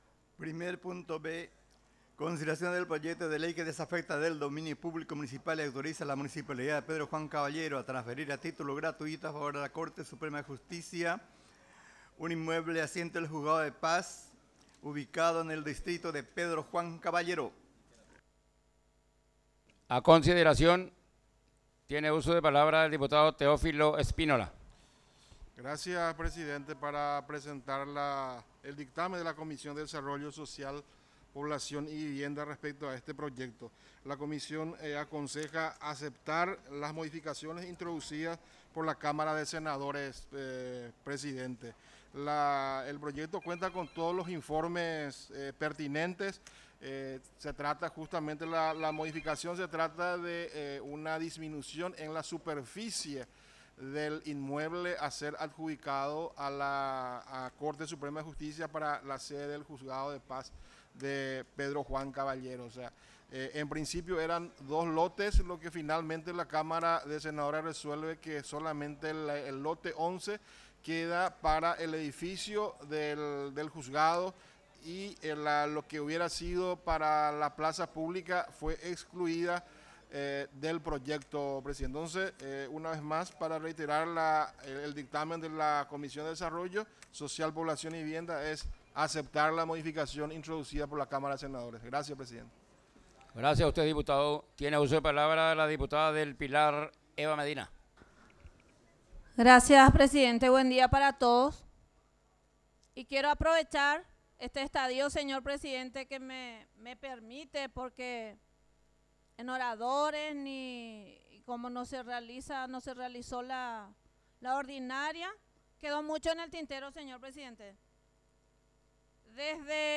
Sesión Extraordinaria, 15 de marzo de 2023